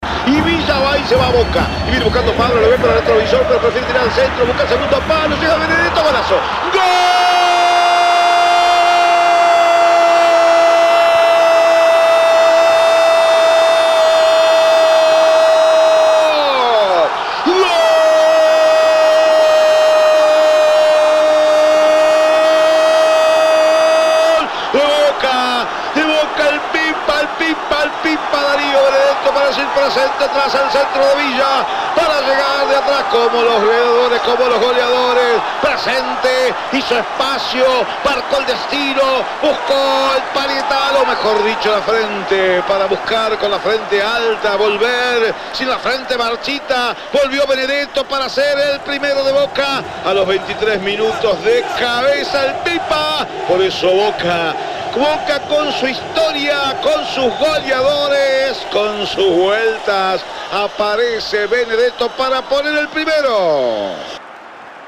GOL-DE-BOCA-01-EDITADO-.mp3